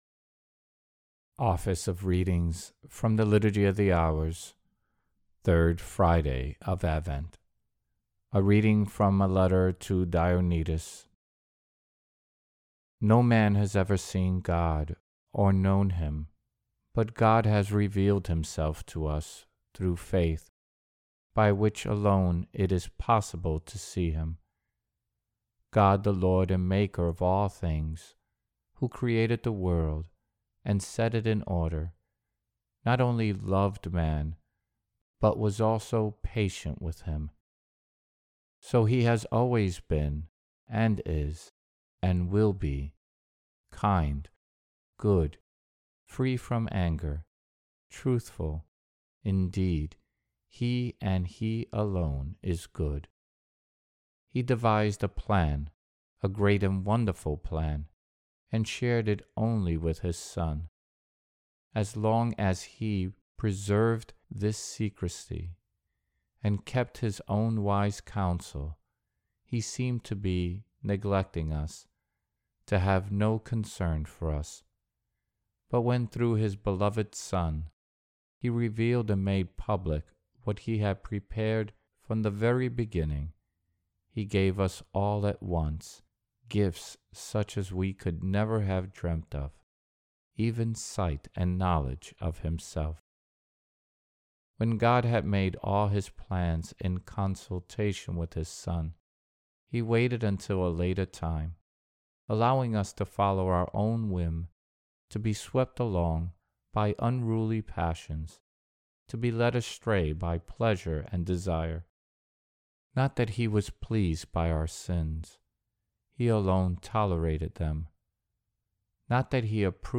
Office of Readings – 3rd Friday of Advent